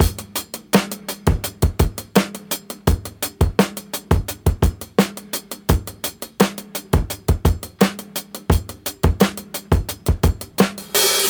• 85 Bpm Drum Loop Sample E Key.wav
Free breakbeat sample - kick tuned to the E note. Loudest frequency: 3849Hz
85-bpm-drum-loop-sample-e-key-eab.wav